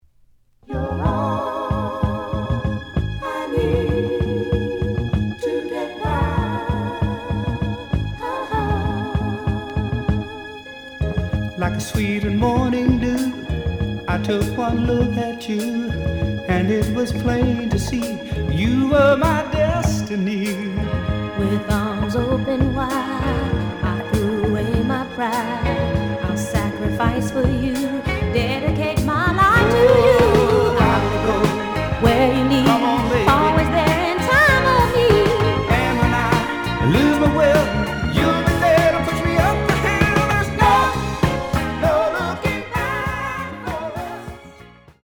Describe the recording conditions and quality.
(Mono) The audio sample is recorded from the actual item.